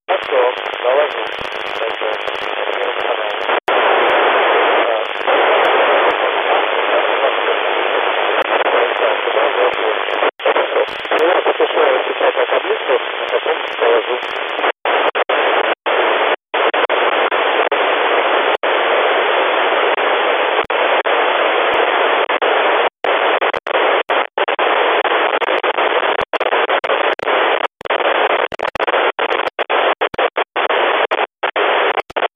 Первый опыт приема МКС